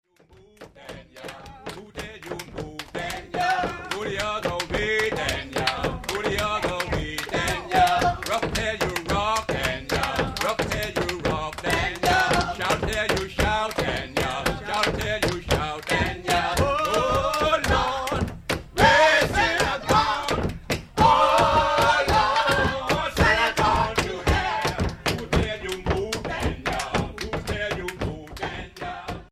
McIntosh County Shouters: Slave Shout Songs from the Coast of Georgia
This song accompanies one of the more active examples of shouts. The religious content of the shout songs was clear enough from the text, but hidden messages carrying protests against slavery and jokes on the master, eluded earlier white observers.
The recordings included here made over a period of two years in McIntosh and Glynn Counties in Georgia. Some performances were recorded at the 1983 Sea Island Festival on St. Simon's; other songs were recorded in two recording sessions in the annex of Mt. Calvary Baptist Church.